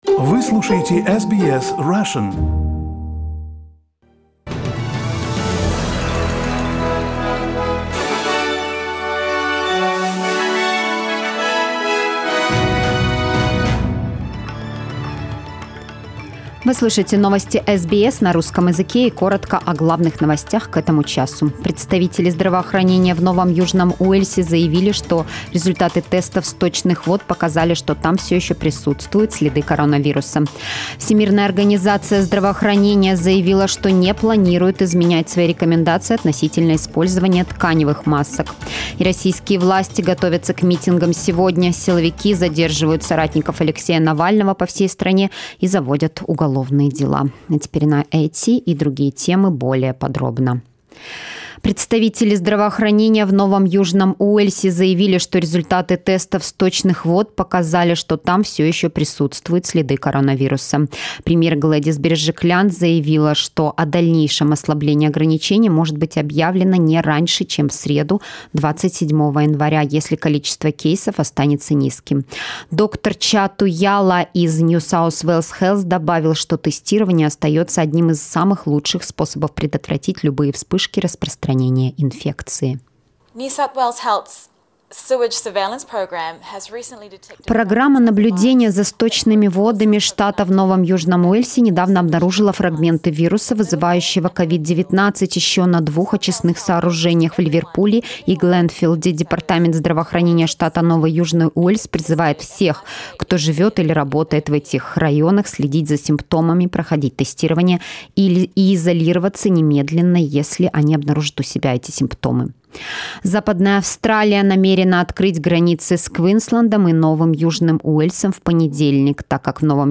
Новостной выпуск за 23 января